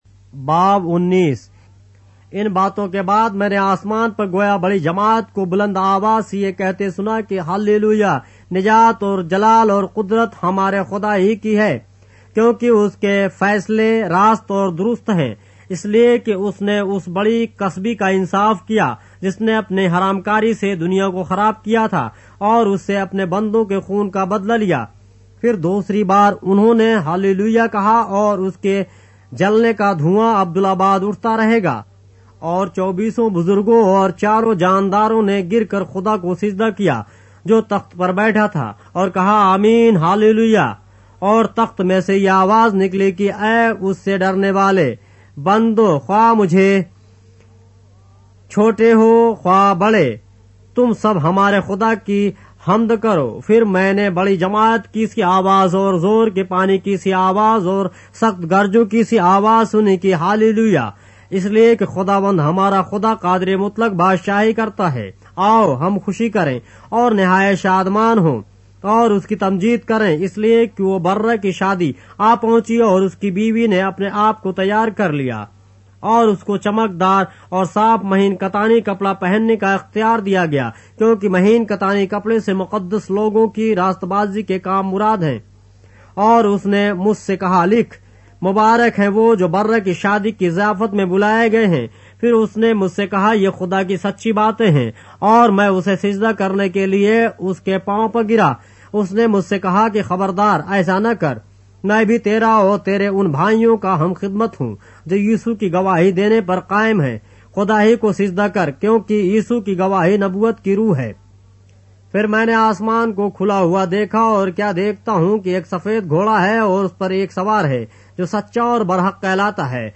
اردو بائبل کے باب - آڈیو روایت کے ساتھ - Revelation, chapter 19 of the Holy Bible in Urdu